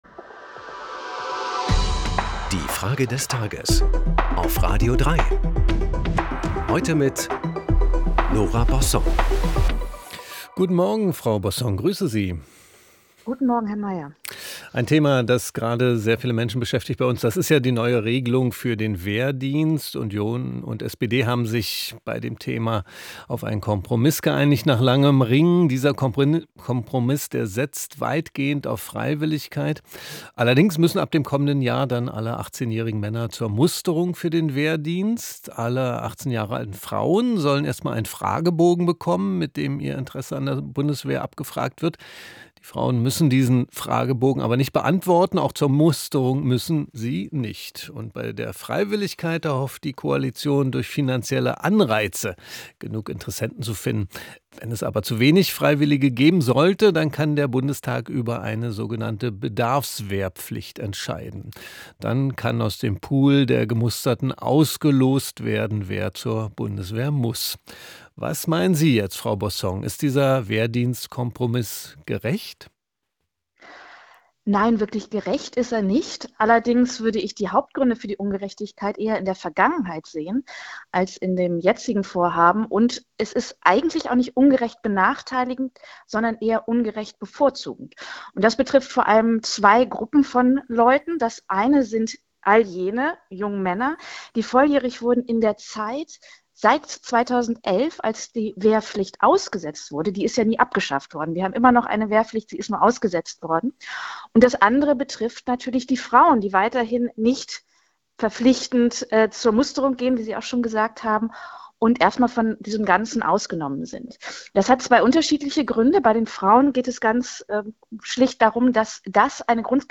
Frage des Tages auf radio3 an die Schriftstellerin Nora Bossong.